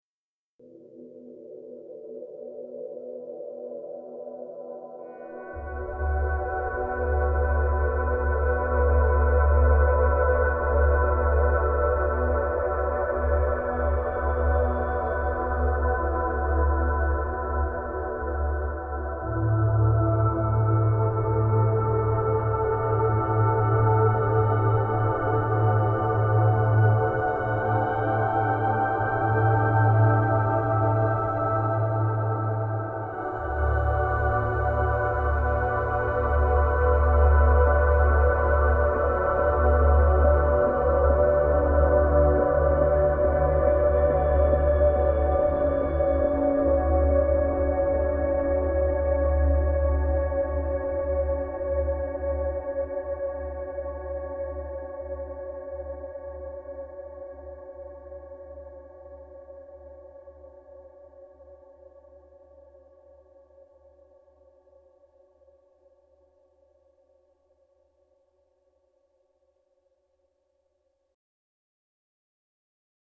Ambient
Ambient1.mp3